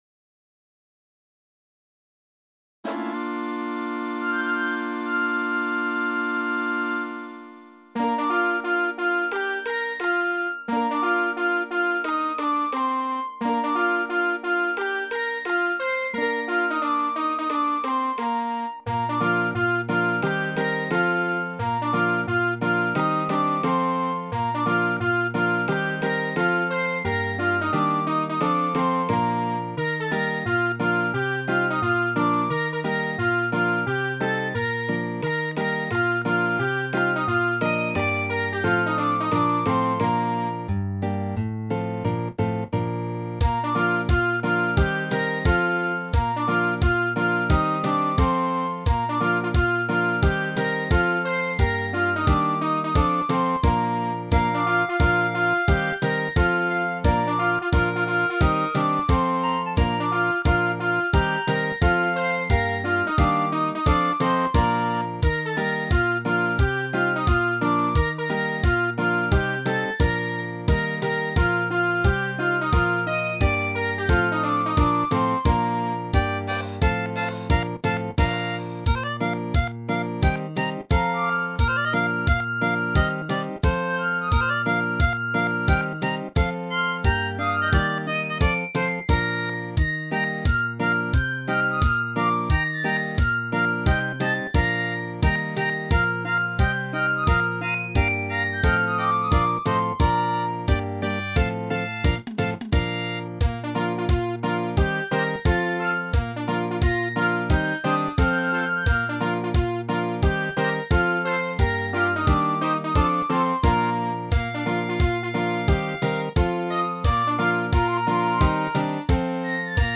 季節によって歌詞は変わるので細かいフレーズは追っていない。